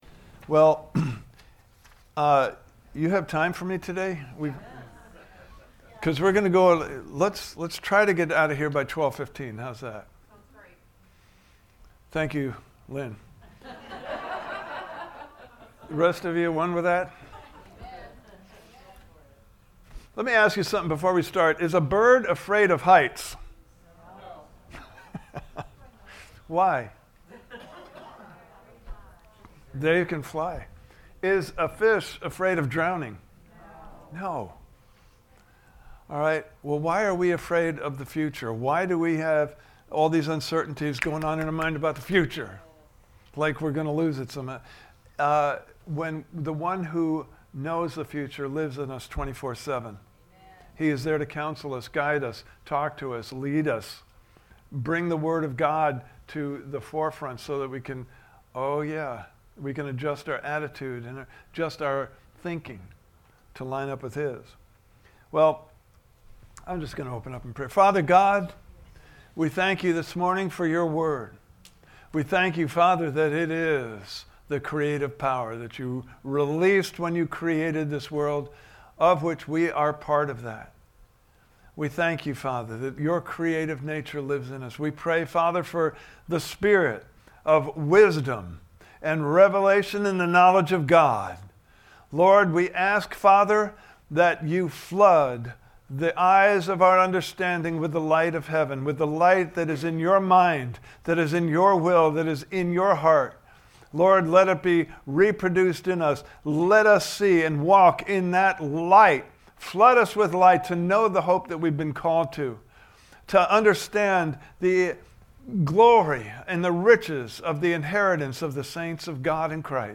Series: Walking With the One Who Knows the Future Service Type: Sunday Morning Service « Part 3